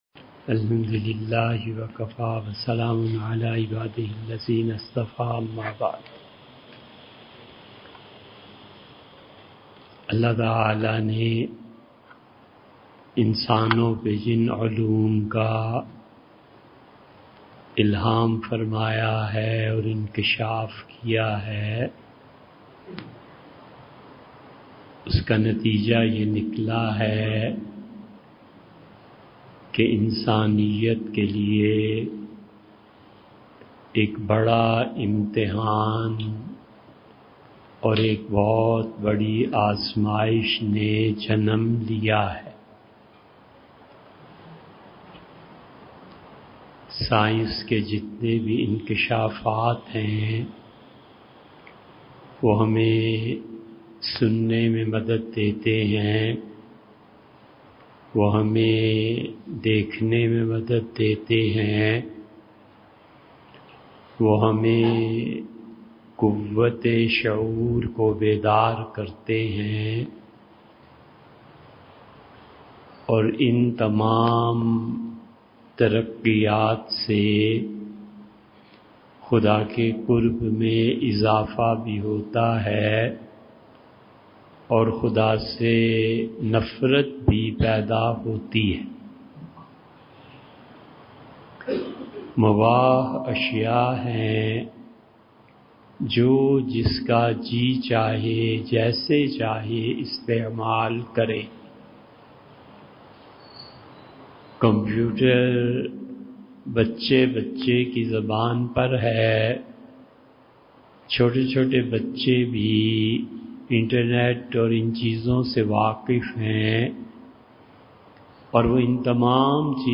Modern Challenges Facing the New Generation and How to Tackle Them | Bayan after Khatme-Quran at Masjid Usman